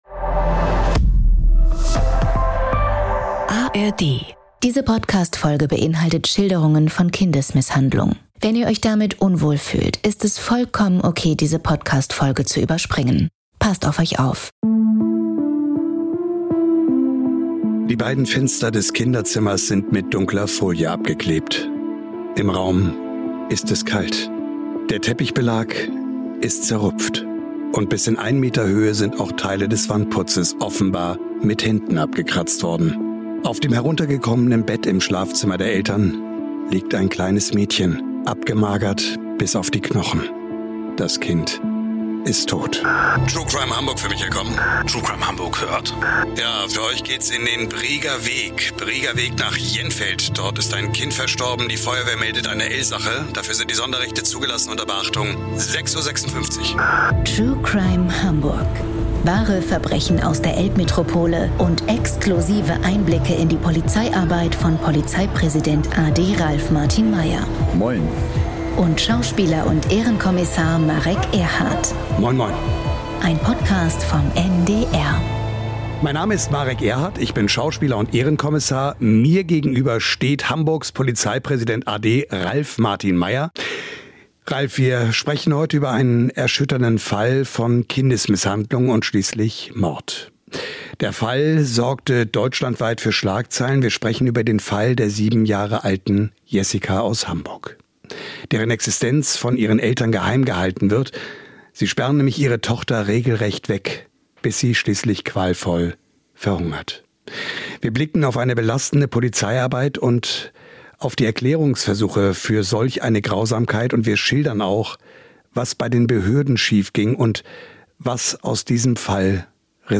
Im Gespräch mit Schauspieler und Ehrenkommissar Marek Erhardt spricht Hamburgs Polizeipräsident a.D. Ralf Martin Meyer